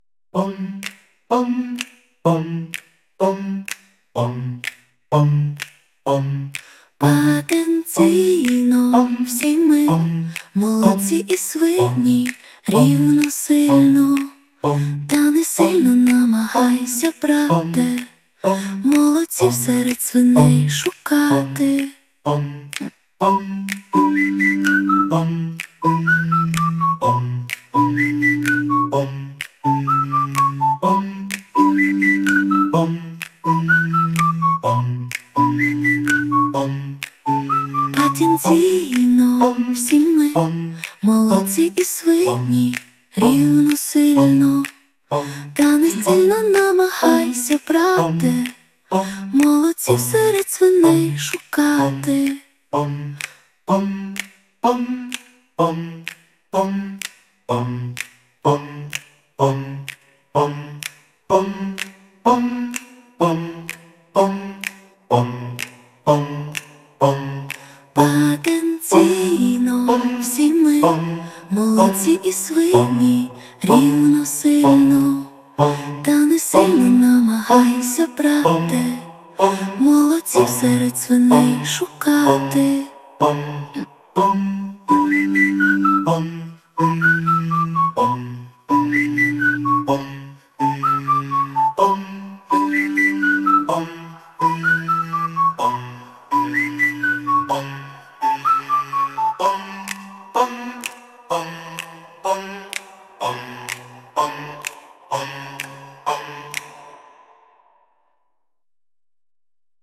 Музика і виконання ШІ
ТИП: Пісня
СТИЛЬОВІ ЖАНРИ: Драматичний